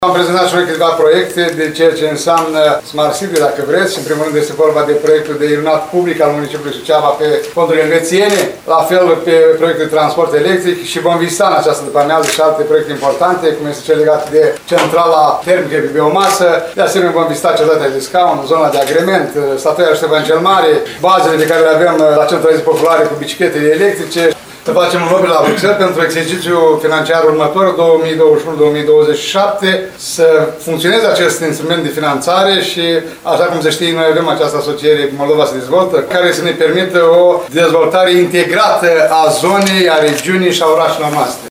Gazda întâlnirii, primarul ION LUNGU, a declarat că aceste schimburi de experiență sunt benefice deoarece permit elaborarea unor proiecte comune de durată.